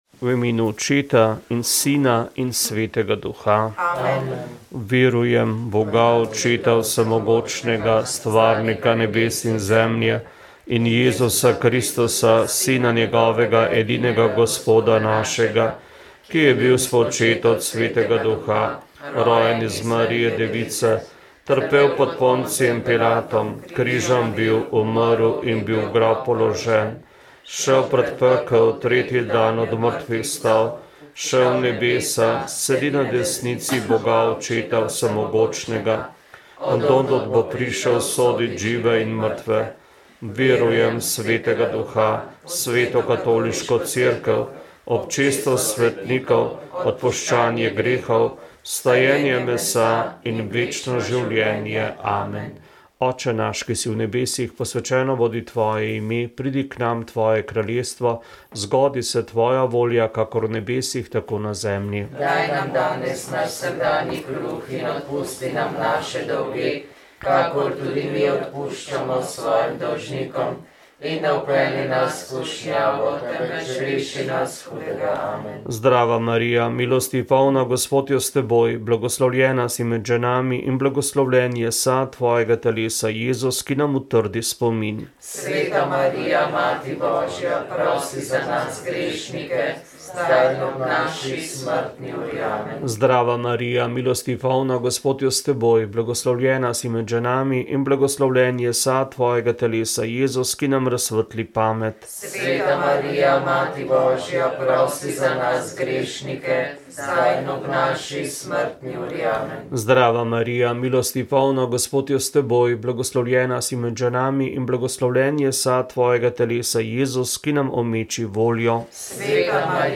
V 16. epizodi podkasta RAST smo gostili dr. Jožeta Podgorška, predsednika Kmetijsko gozdarske zbornice Slovenije. Prisluhnite, kako vodenje te največje kmečke stanovske organizacije vidi po letu dni »zelo razburkane plovbe« in kakšni so izzivi, ki jih napovedujejo pretresi na kmetijskem področju v prihodnjem letu.